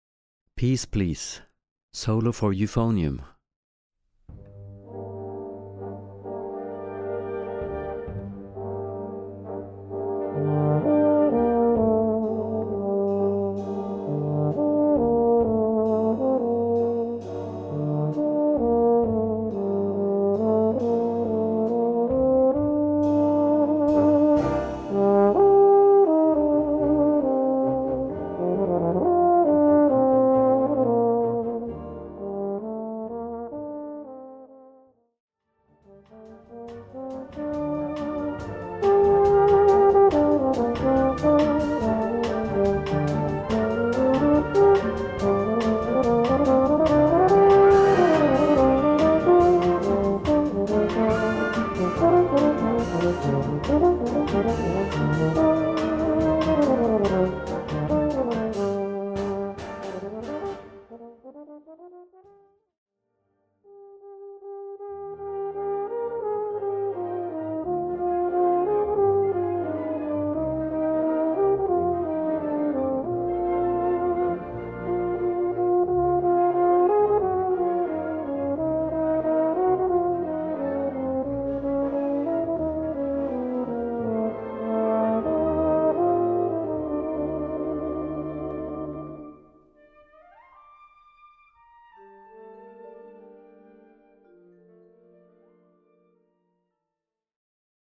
Gattung: Solo für Euphonium und Blasorchester
Besetzung: Blasorchester